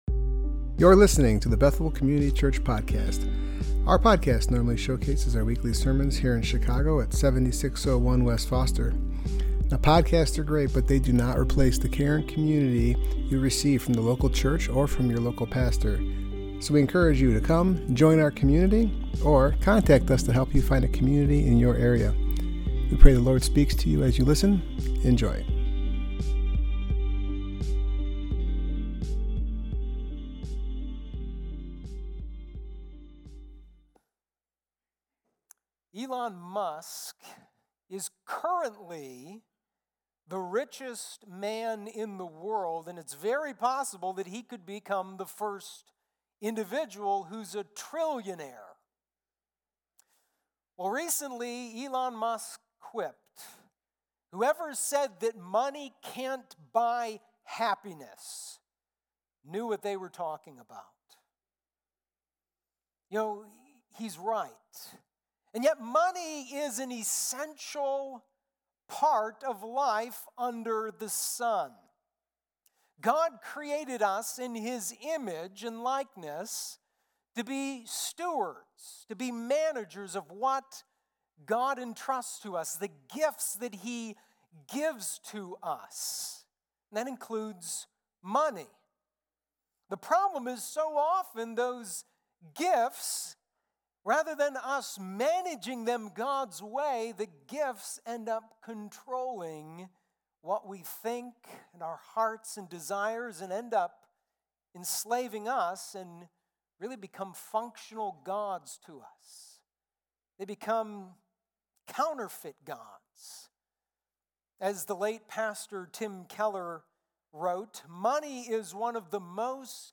Passage: Ecclesiastes 5:8-6:12 Service Type: Worship Gathering